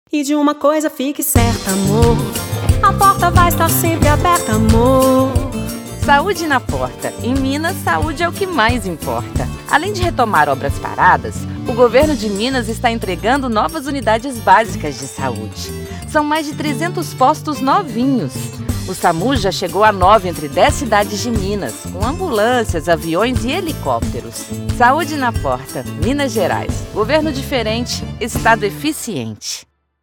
Spots
FILADELFIA-GOVMINAS-SAUDE-NA-PORTA-OBRAS-E-SAMU-SPOT30.mp3